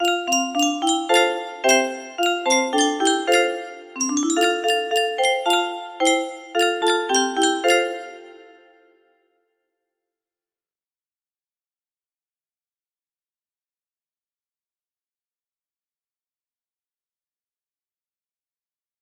Au clair de la lune music box melody